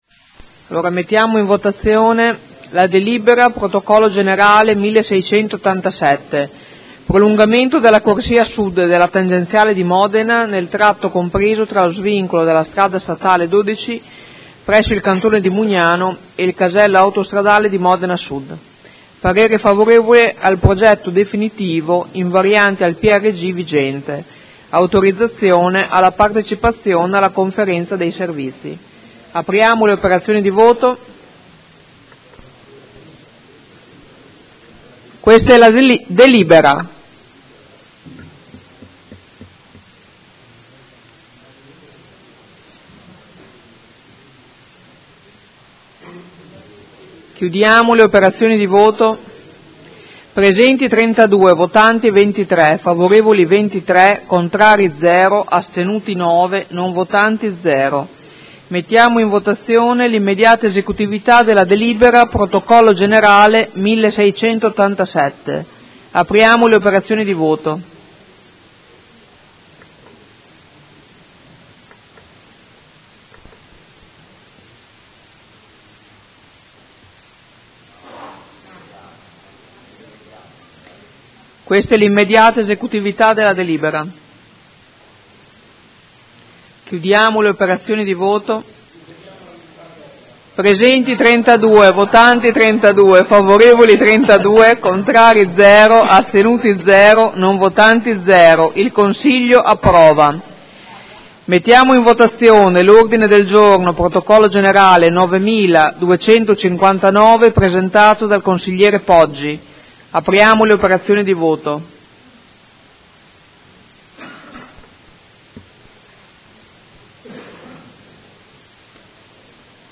Presidente
Seduta del 21/01/2016 Proposta di deliberazione: Prolungamento della corsia Sud della tangenziale di Modena, nel tratto compreso tra lo svincolo sulla SS 12 presso il Cantone di Mugnano ed il casello autostradale di Modena Sud.